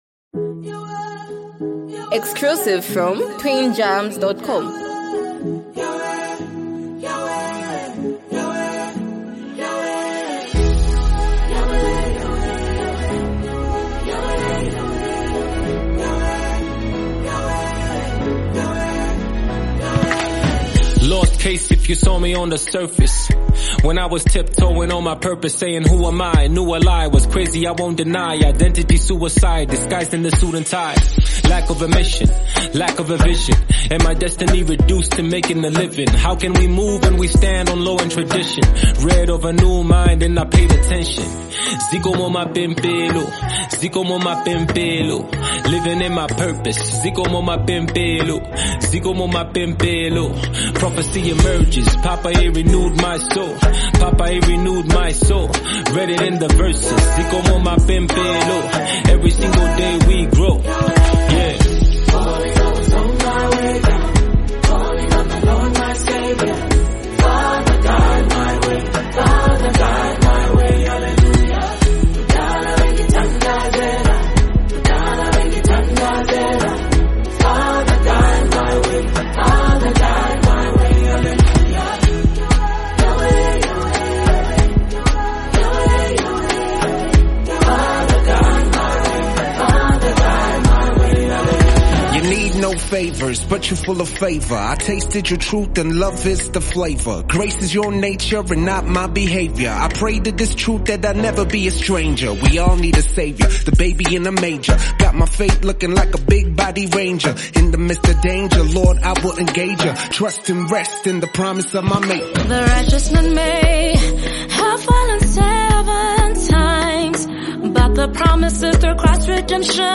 deeply spiritual and uplifting song